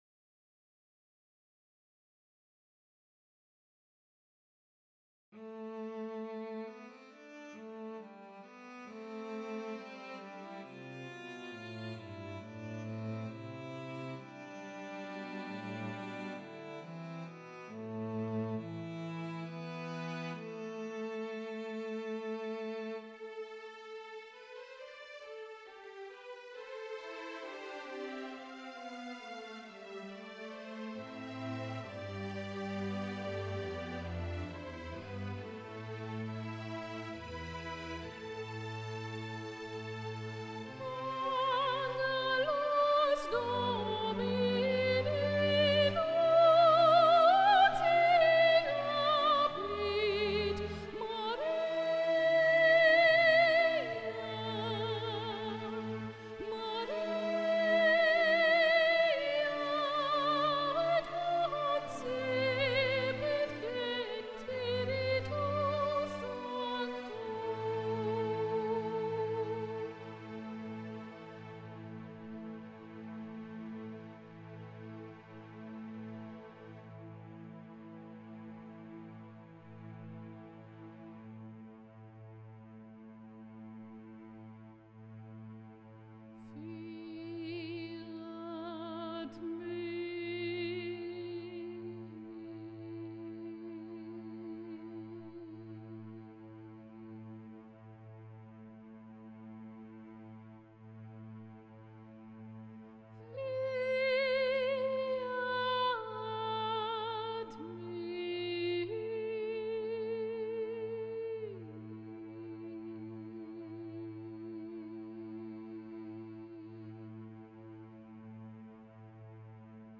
Meditationsgesang